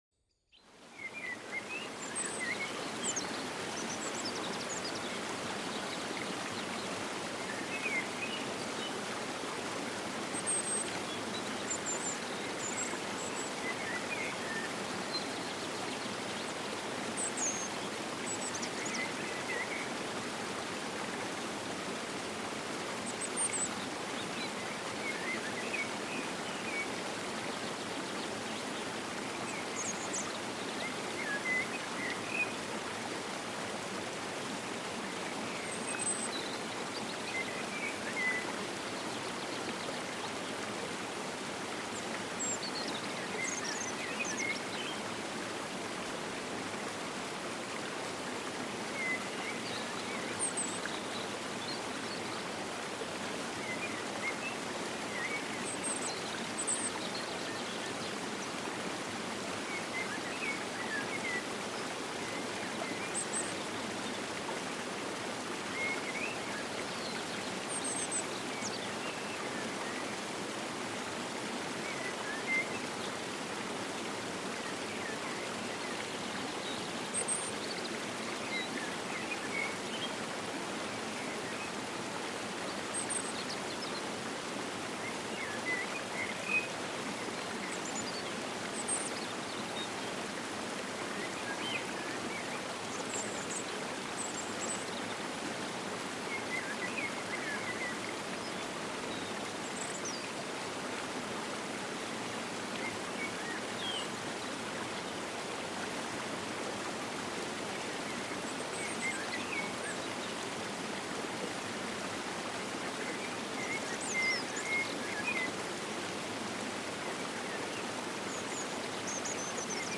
Wald-Kaskade: Wasser rauscht durch grüne Entspannungs-Landschaft